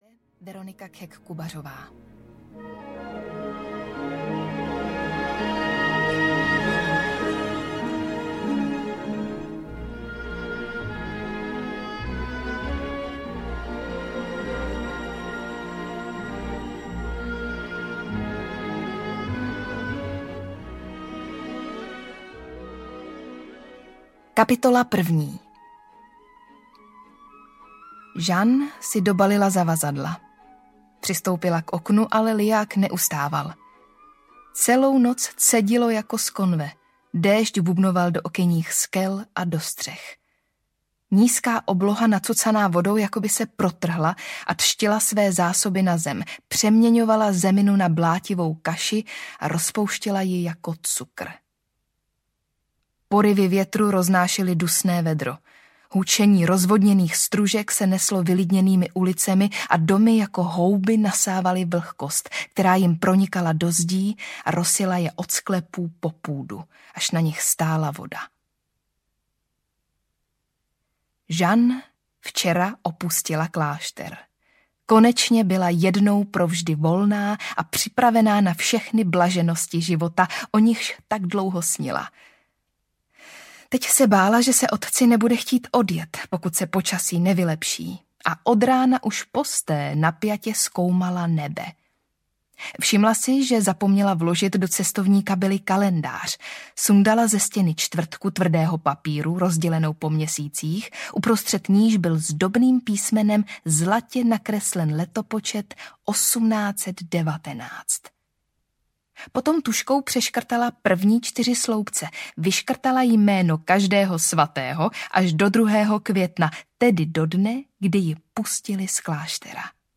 Jeden život audiokniha
Ukázka z knihy
• InterpretVeronika Khek Kubařová